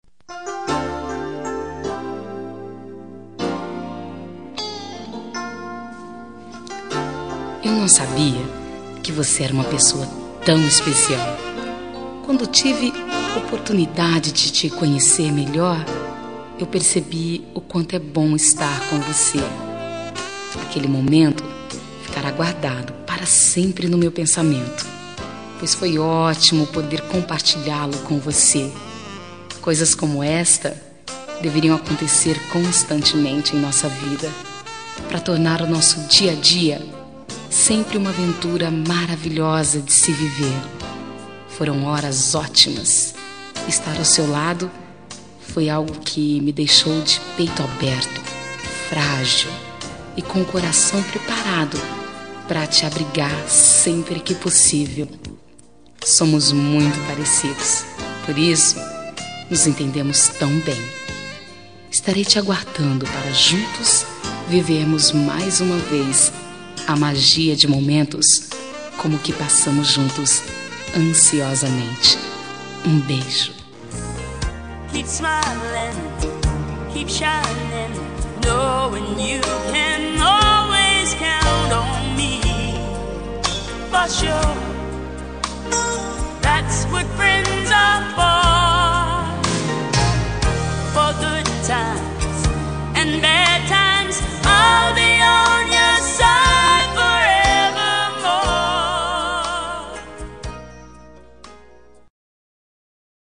Telemensagem Momentos Especiais – Voz Feminina – Cód: 201878 – Adorei te Conhecer